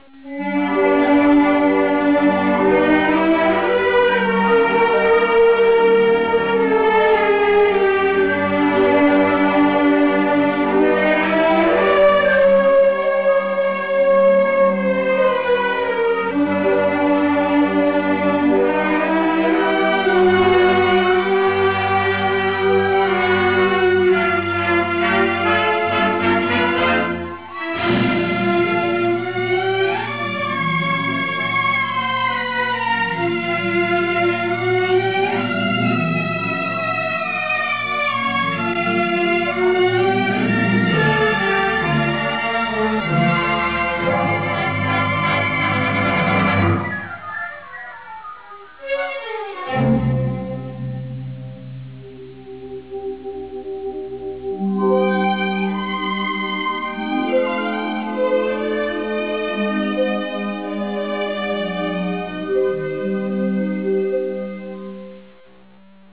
Musica
Track Music